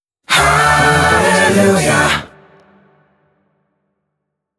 “Hallelujah” Clamor Sound Effect
Can also be used as a car sound and works as a Tesla LockChime sound for the Boombox.